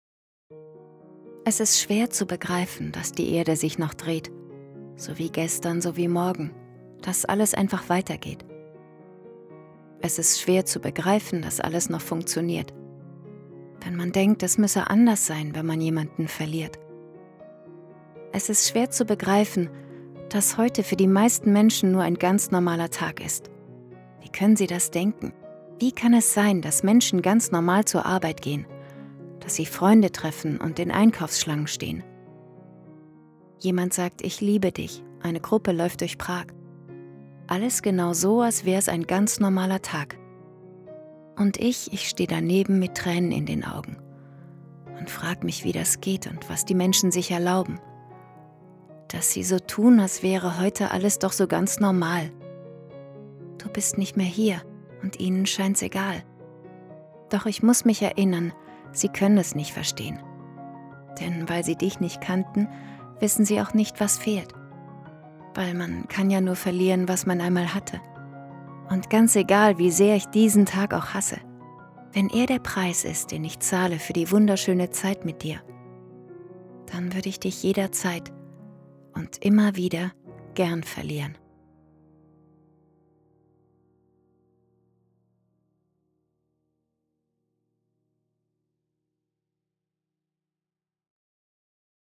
Trauergedicht für einen geliebten Menschen